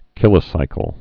(kĭlə-sīkəl)